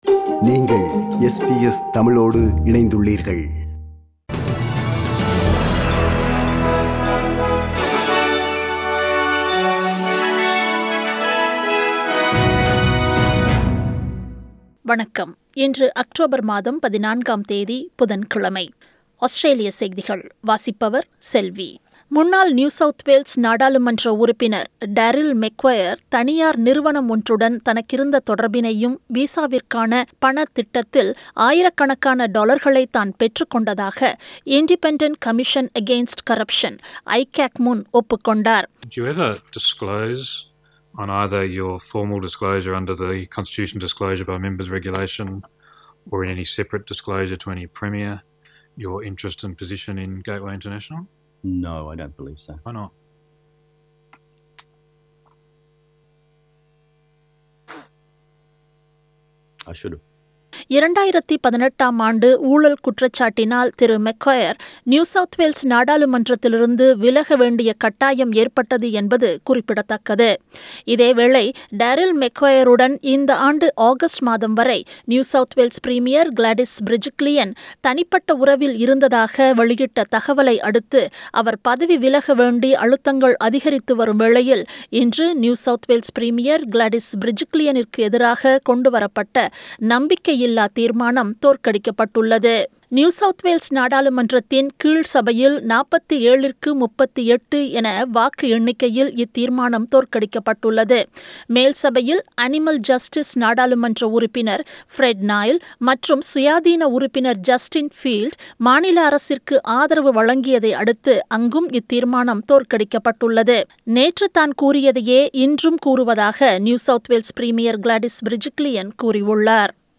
Australian news bulletin for Wednesday 14 October 2020.